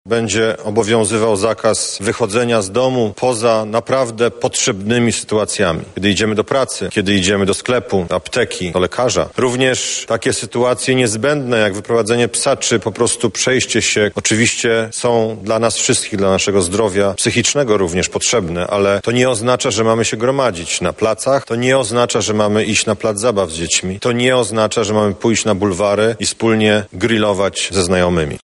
Obserwacja krajów które są wokół nas pokazuje, że umiarkowane ograniczenie kontaktów międzyludzkich może prowadzić do przesunięcia fali zachorowań – mówi Minister Zdrowia, Łukasz Szumowski.